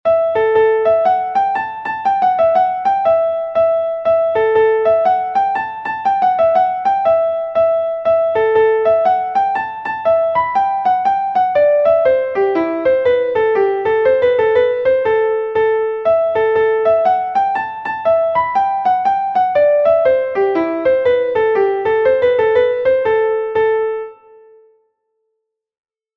Gavotte from Brittany